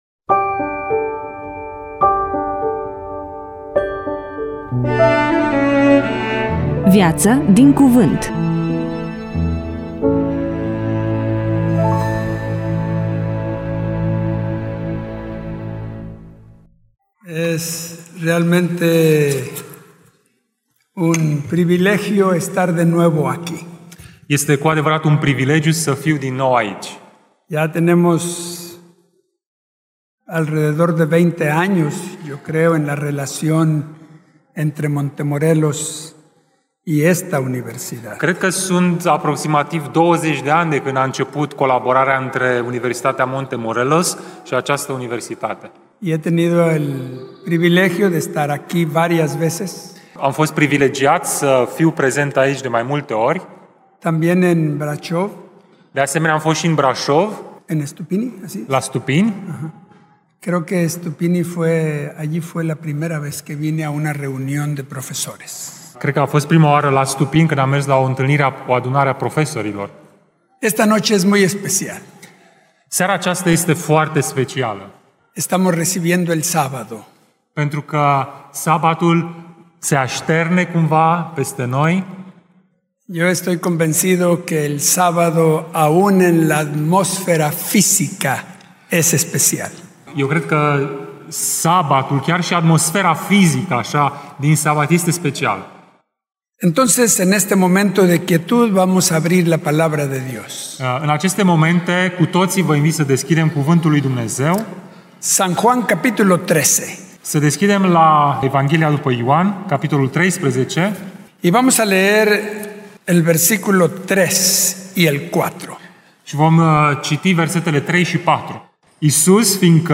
EMISIUNEA: Predică DATA INREGISTRARII: 05.07.2025 VIZUALIZARI: 176